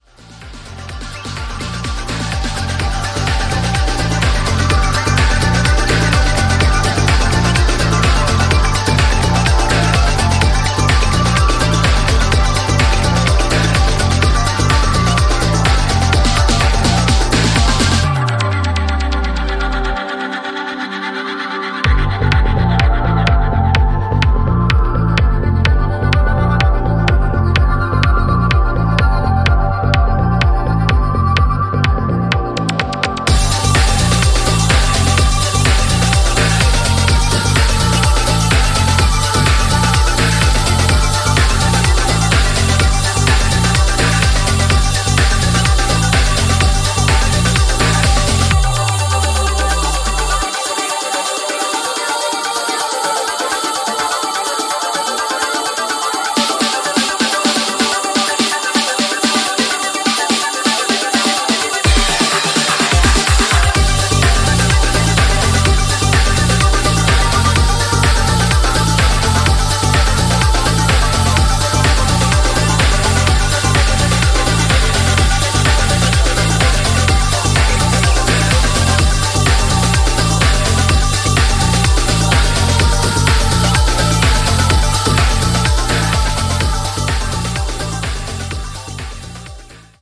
トランシーなシンセリフがフロアを巻き込んでいく